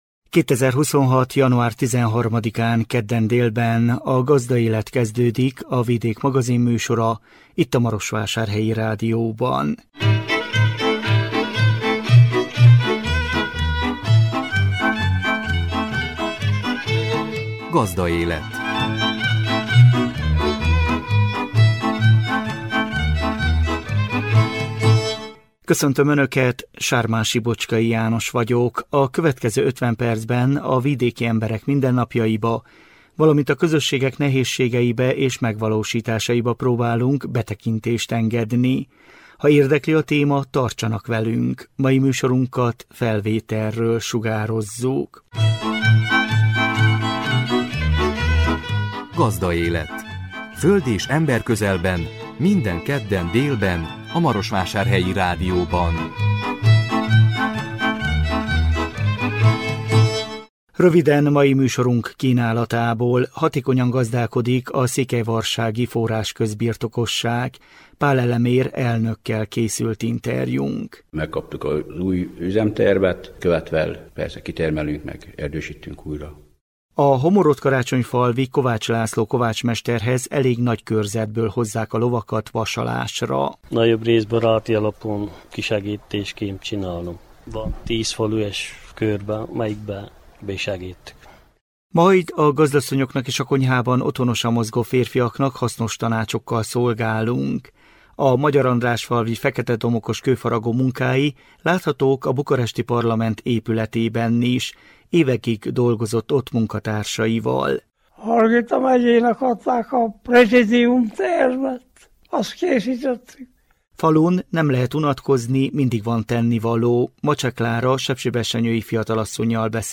interjúnk